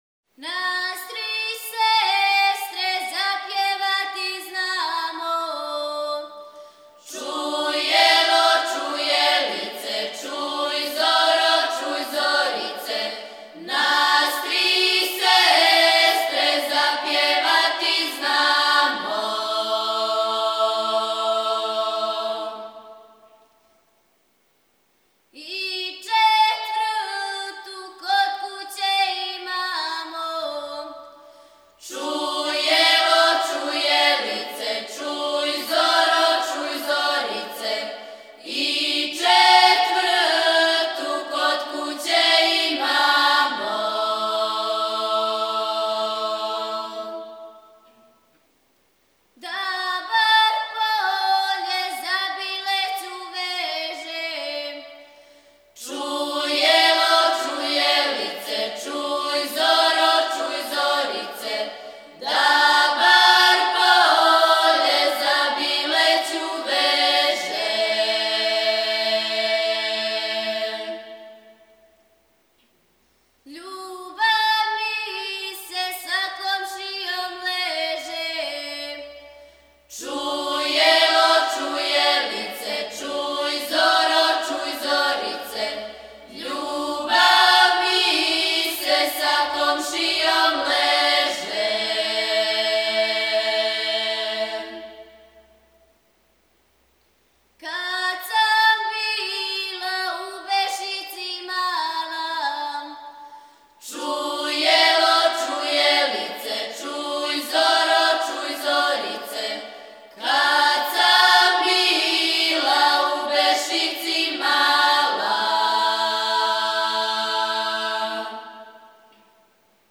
Снимци КУД "Др Младен Стојановић", Младеново - Девојачка и женска певачка група (5.9 MB, mp3) О извођачу Албум Уколико знате стихове ове песме, молимо Вас да нам их пошаљете .